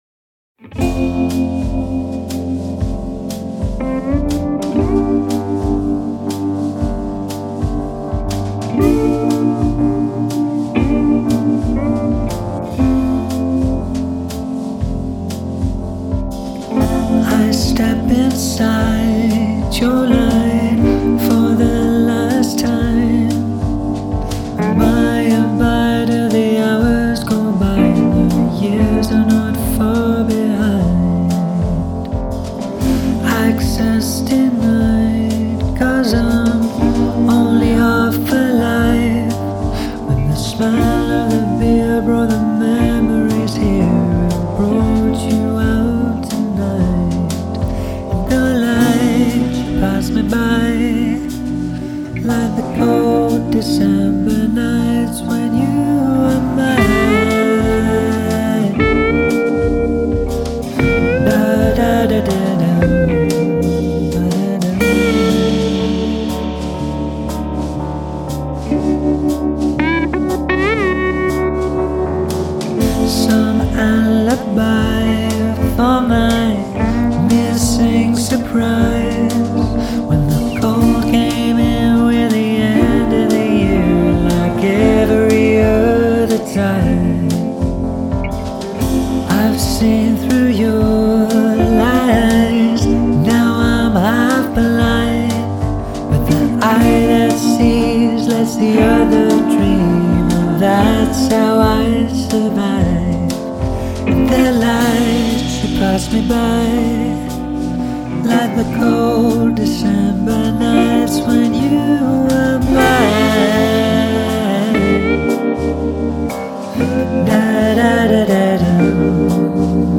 Overall, things were pretty nice until the 2 minute mark when the vocals just overwhelmed everything, and sound completely overdriven.
Otherwise, the song is good, nice harmony and backing vocals.